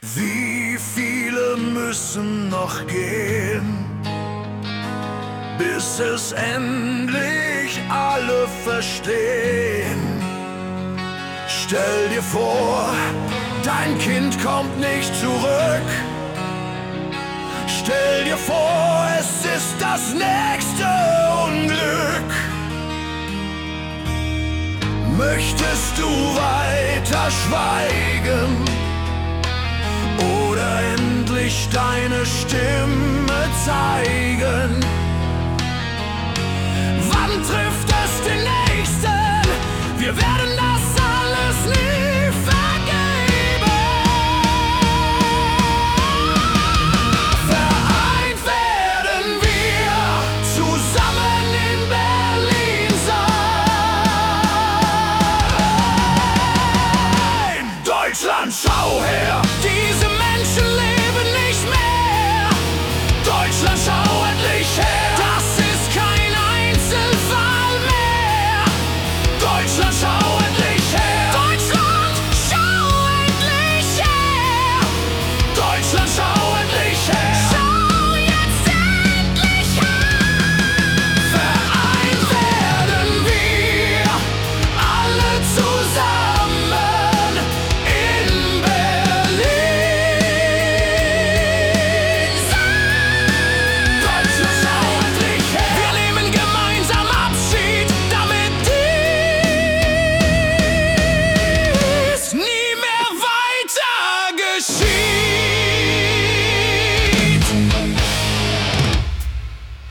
Rock Version Mp 3